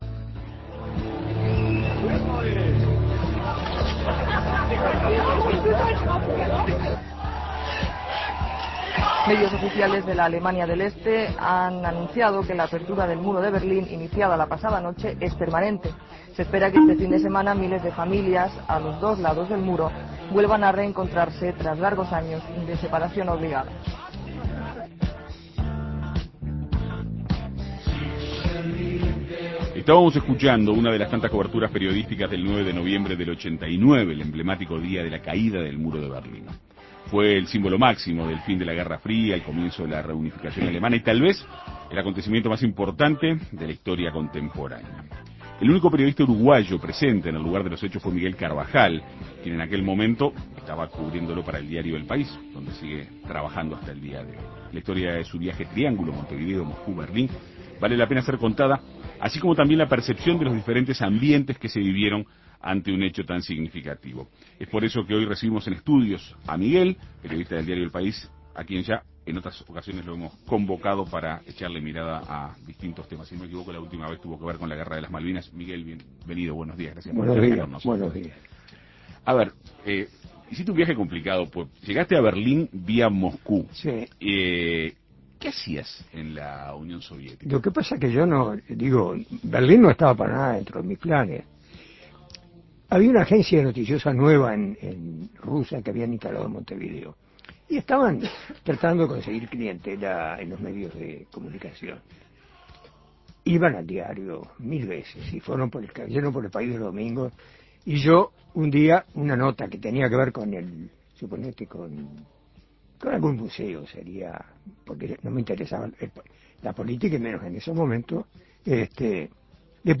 En Perspectiva Segunda Mañana dialogó con el profesional para conocer los detalles de su historia.